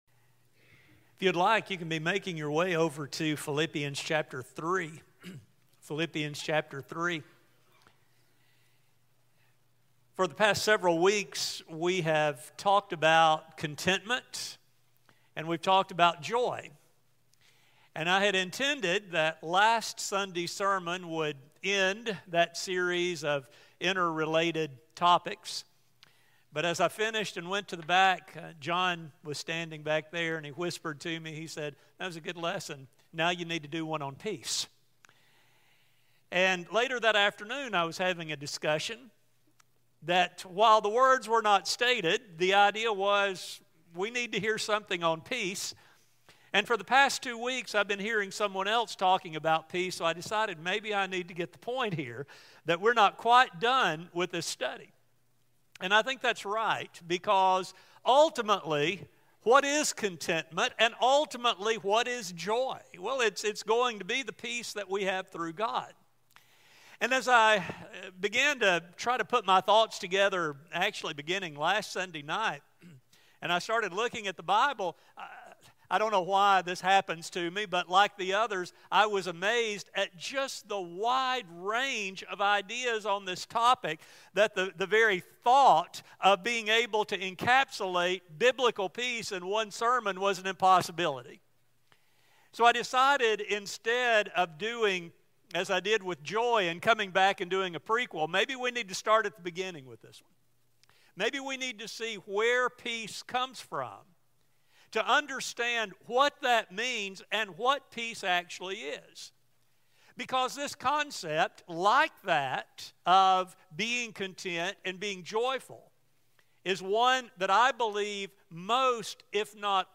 In this study, we will explore what God promises about peace and how this promise is based on His unchangeable character. A sermon